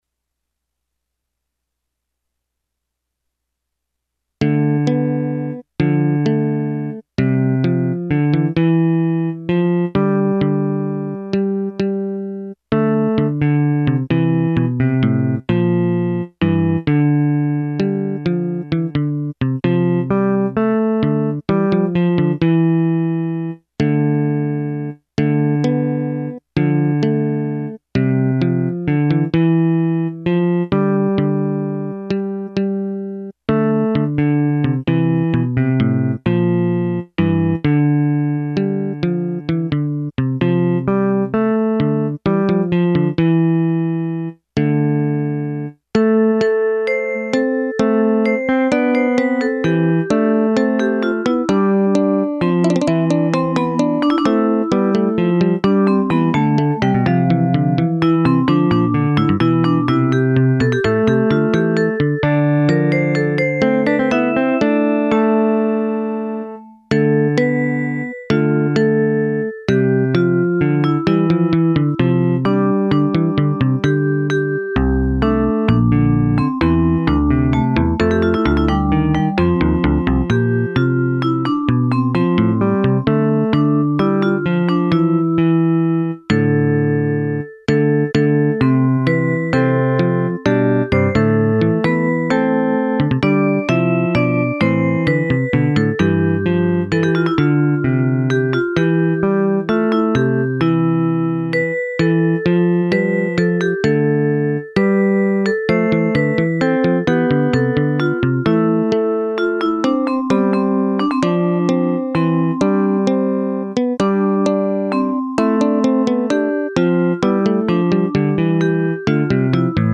neomedieval chanson